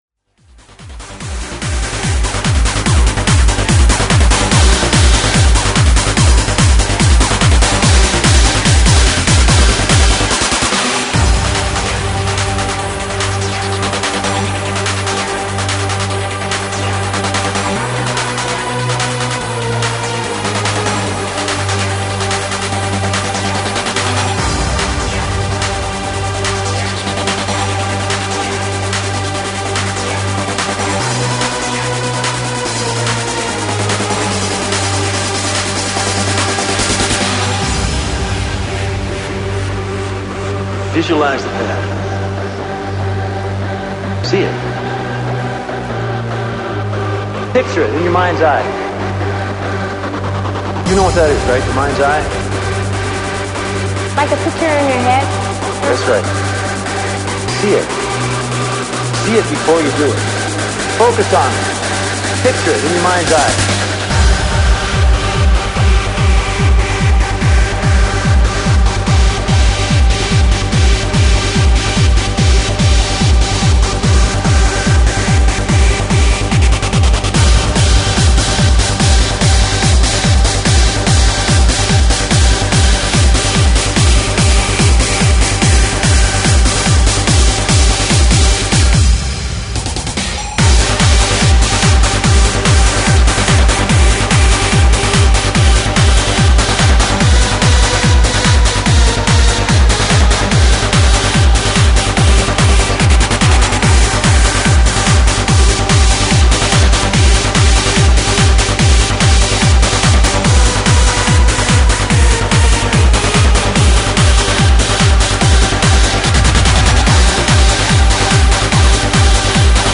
Hard House/Hard Trance/Freeform